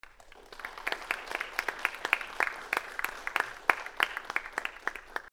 / C｜環境音(人工) / C-60 ｜その他 / 拍手_小ホールコンサート_観客約30人
21 拍手 近い人あり